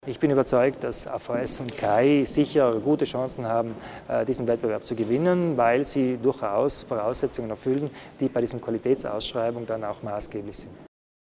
Landeshauptmann Kompatscher erläutert die Neuheiten zum Thema Führung der Schutzhütten